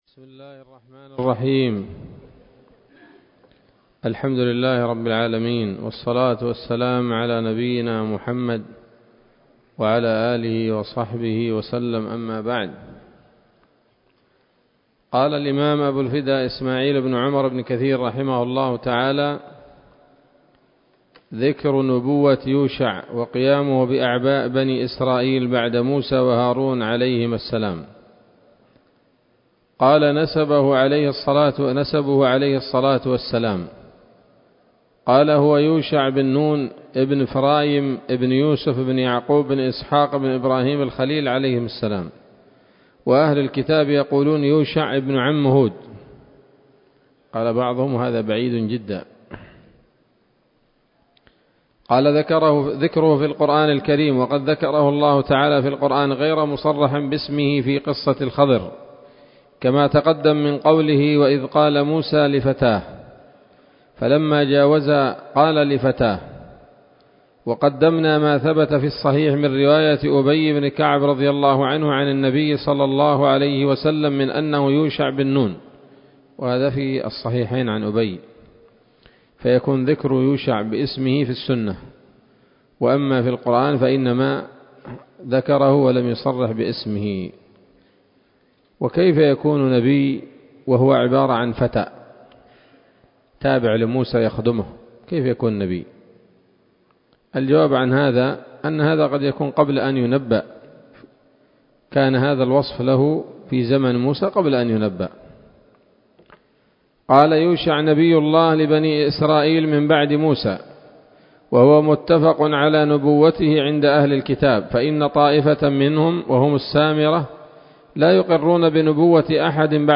‌‌الدرس السابع بعد المائة من قصص الأنبياء لابن كثير رحمه الله تعالى